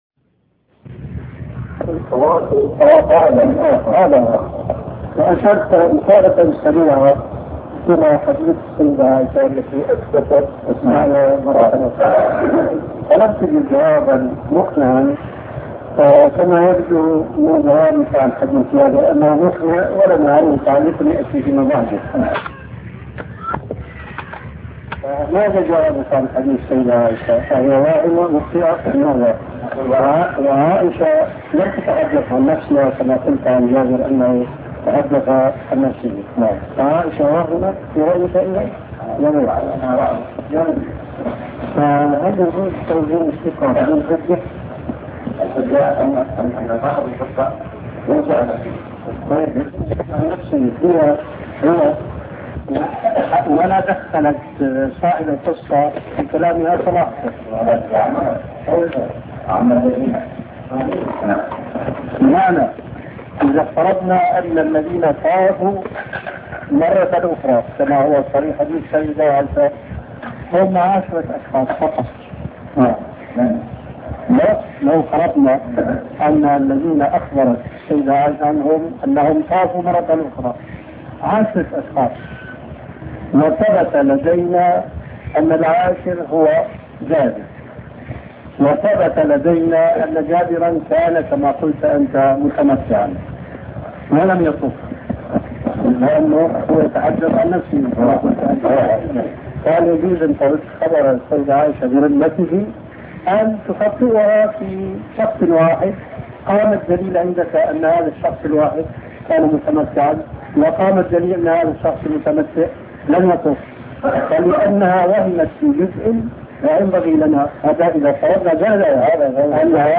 مناقشة طلاب العلم مع الشيخ الألباني حول حديث عائشة لما أمرها رسول الله صلى الله عليه وسلم بالخروج إلى الحل للعمرة بعد الحج .